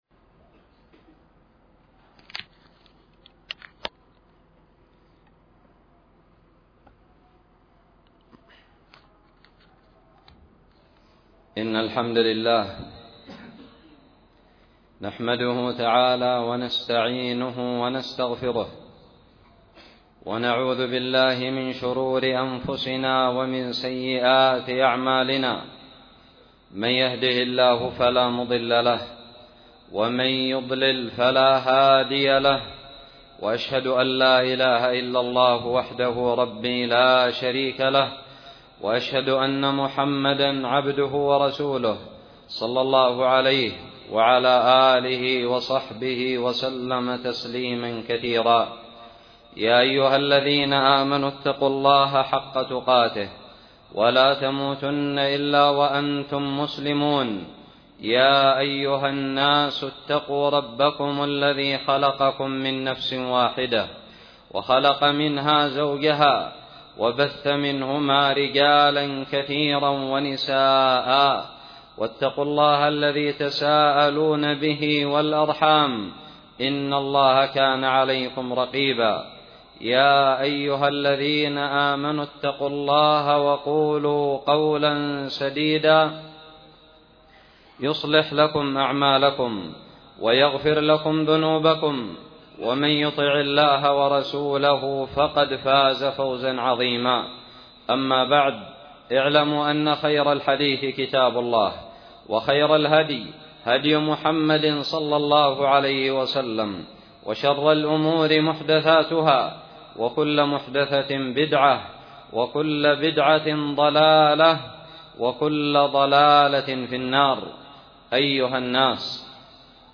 خطب الجمعة
ألقيت بدار الحديث السلفية للعلوم الشرعية بالضالع في 7 رمضان 1438هــ